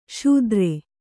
♪ śudre